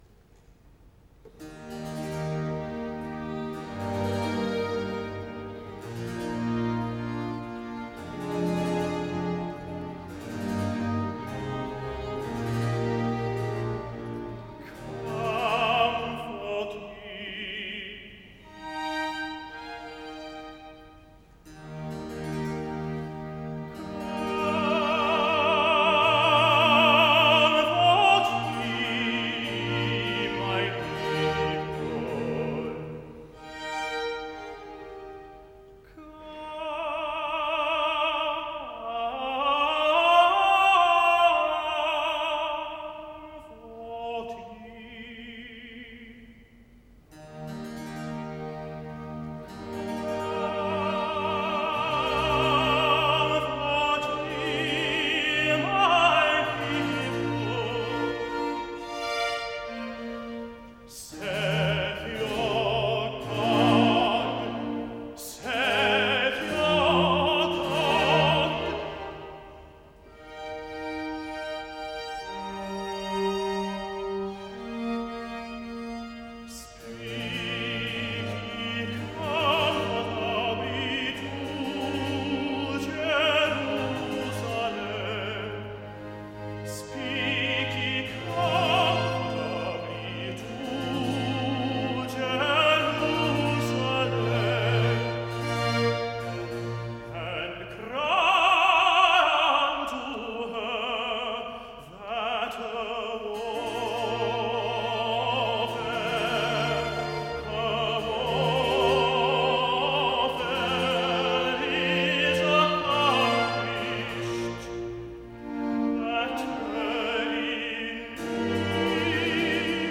Recitative-tenor